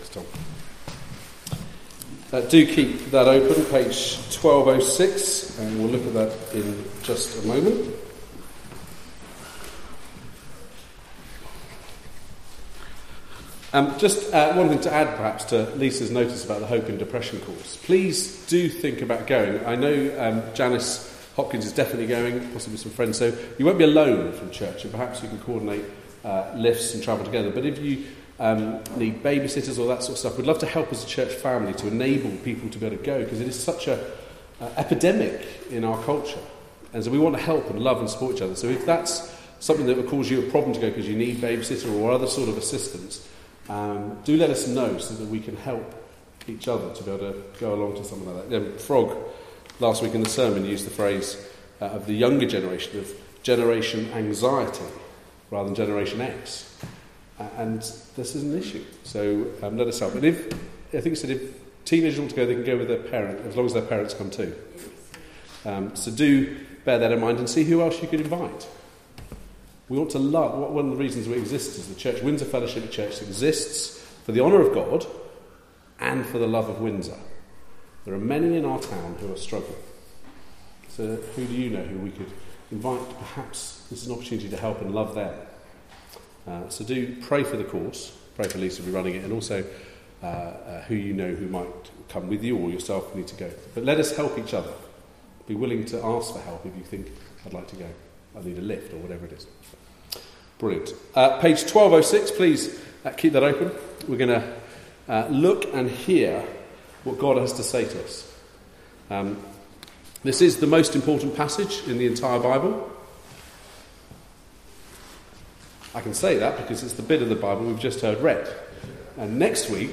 Passage: Hebrews 8: 1-13 Service Type: Weekly Service at 4pm